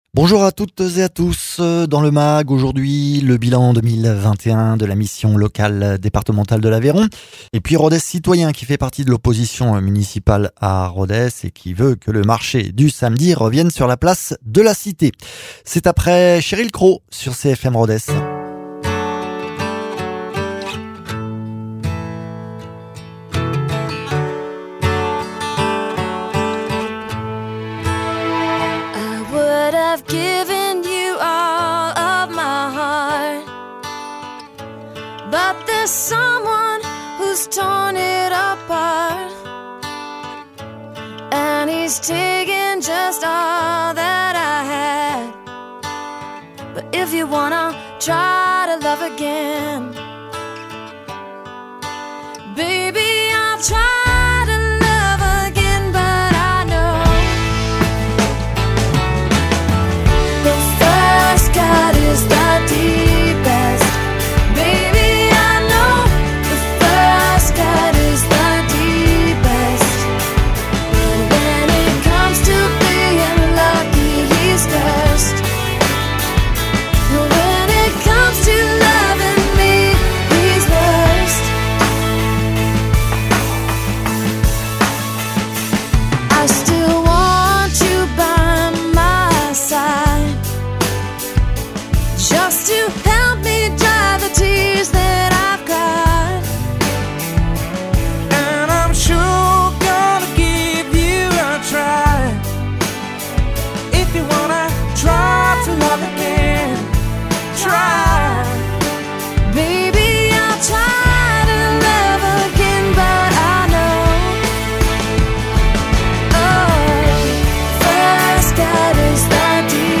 Invité(s) : Marion Berardi, élue municipale d’opposition ’’Rodez citoyen’’